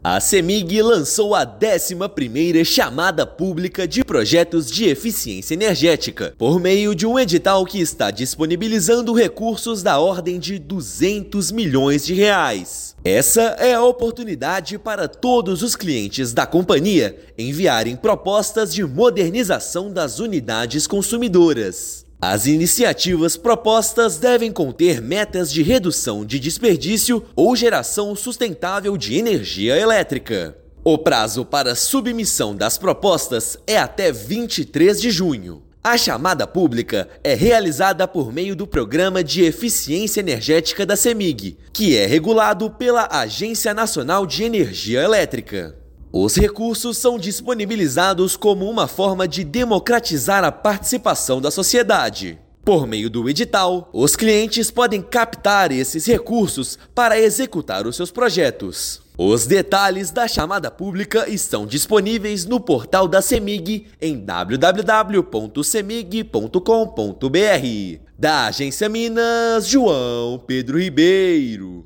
[RÁDIO] Cemig lança edital de R$ 200 milhões para captação de projetos de eficiência energética
Em dez anos de Chamadas Públicas, Programa de Eficiência Energética já investiu mais de R$ 121 milhões em 150 propostas de clientes aprovadas. Ouça matéria de rádio.